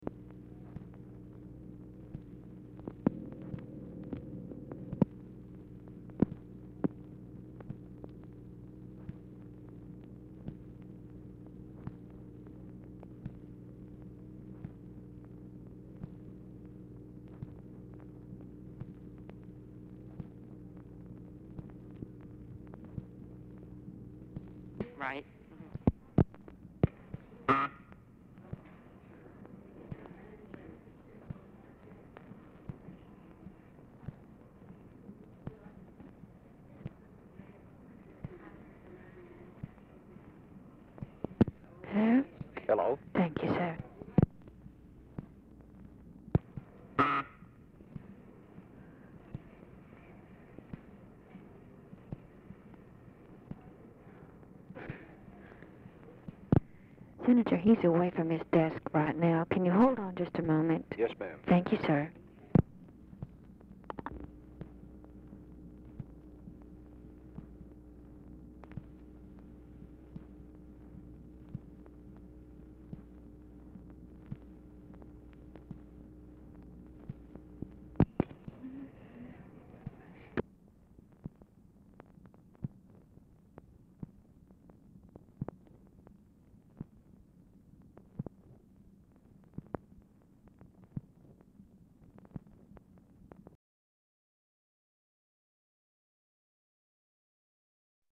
Telephone conversation # 1813, sound recording, OFFICE SECRETARY and MIKE MANSFIELD, 2/1/1964, 4:45PM | Discover LBJ
Format Dictation belt
Location Of Speaker 1 Oval Office or unknown location